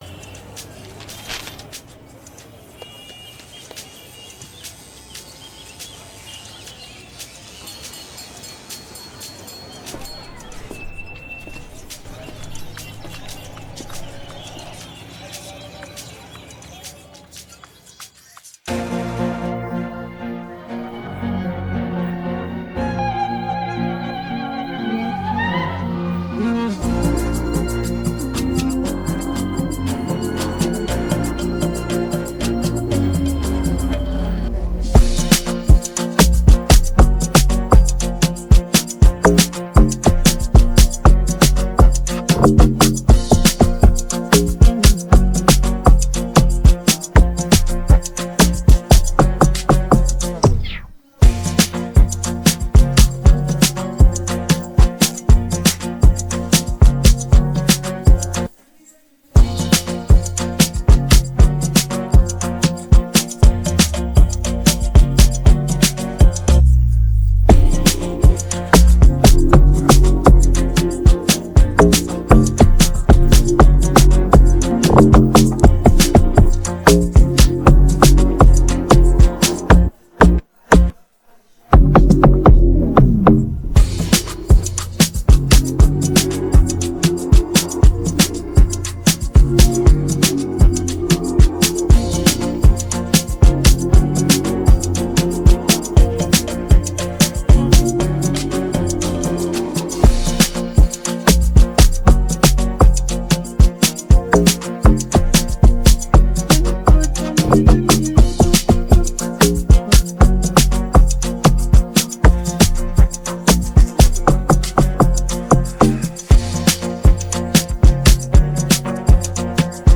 AfrobeatsAmapaino
In the dynamic world of Afrobeat